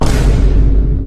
BOOM.mp3